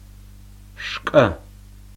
шкӏэ like cup but ejective [1]
^ a b c d e f g h i j k l Ejective consonants, which do not occur in English, are voiceless consonants that are pronounced with pressure from the throat rather than the lungs.